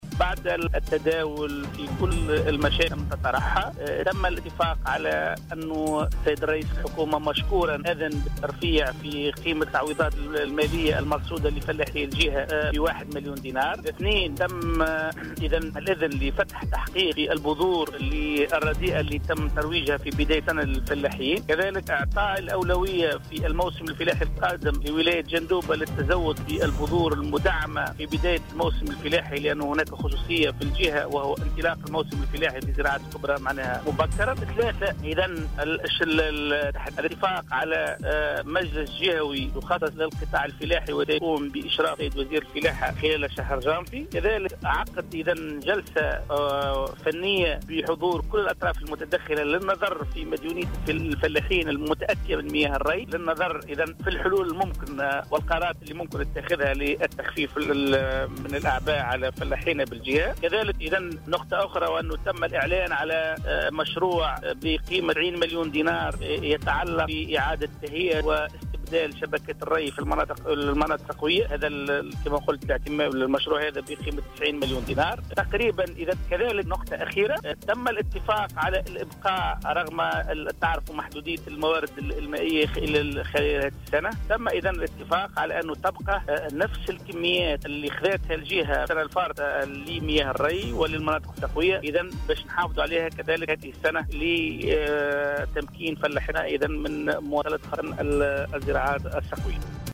وقال والي جندوبة، محمد صدقي بوعون في تصريح لـ"الجوهرة أف أم" إنه تم إقرار جملة من الاجراءات لفائدة فلاحي الجهة من ذلك الترفيع في قيمة التعويضات المالية و فتح تحقيق في البذور الرديئة التي تم ترويجها في بداية السنة للفلاحين وإعطاء الاولوية للجهة للتزود بالبذور المدعمة في الموسم الفلاحي القادم.